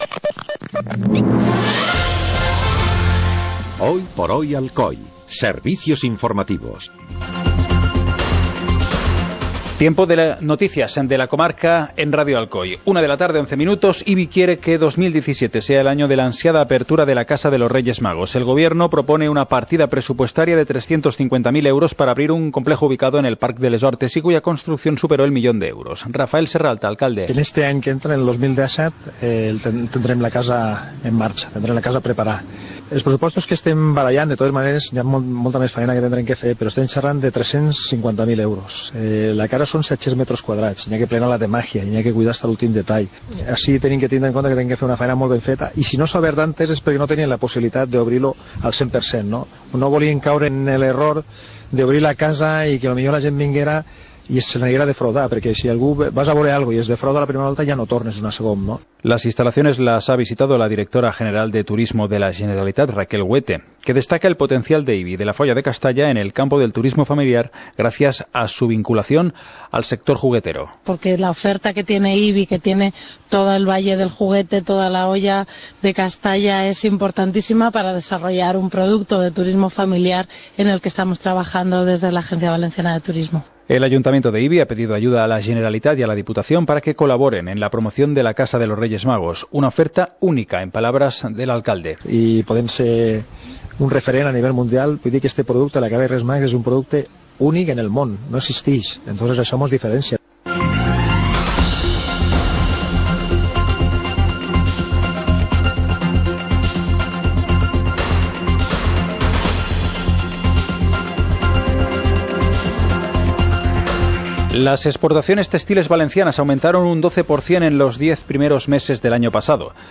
Informativo comarcal - martes, 03 de enero de 2017